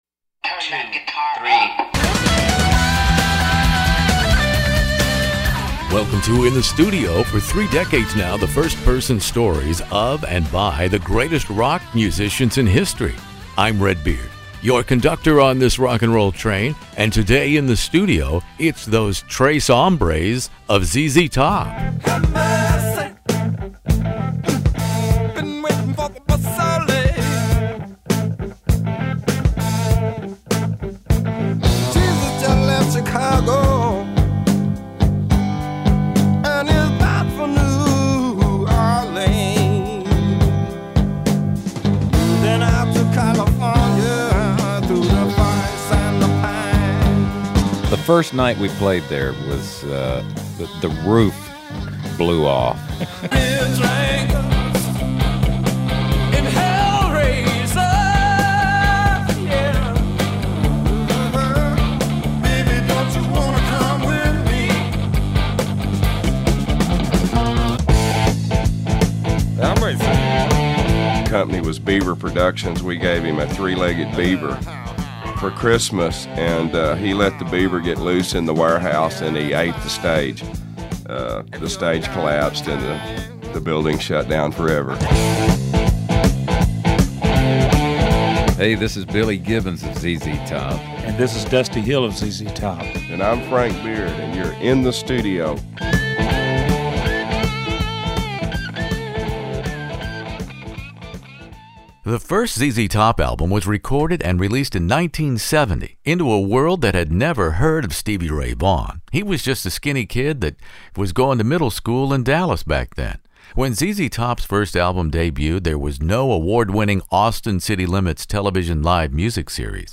ZZ Top interview with Billy Gibbons, the late Dusty Hill, & Frank Beard In the Studio for the fiftieth anniversary of their all-important third album in July 1973, "Tres Hombres".